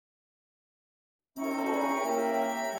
для духового оркестра